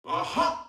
VO_LVL3_EVENT_Aha echec_02.ogg